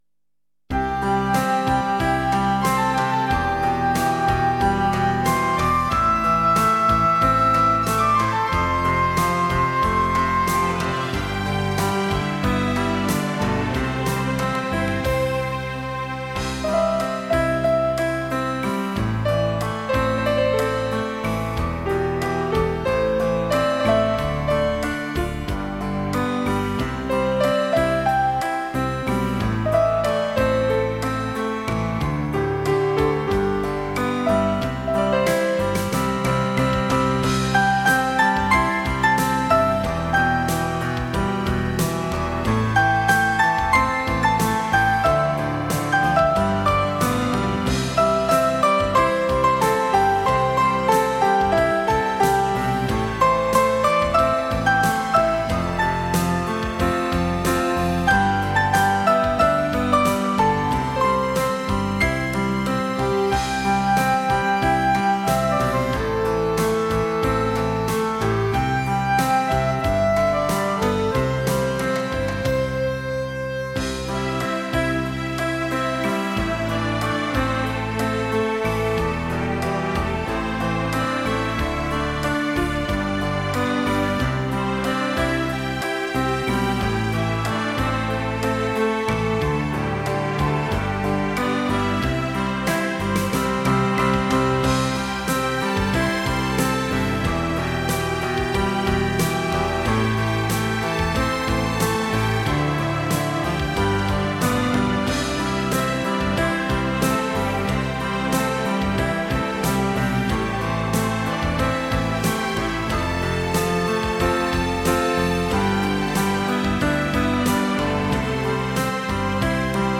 PIANO & ORCHESTRA 乐队伴奏